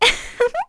Erze-Vox-Laugh_kr.wav